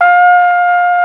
Index of /90_sSampleCDs/Roland L-CD702/VOL-2/BRS_Flugelhorn/BRS_Flugelhorn 1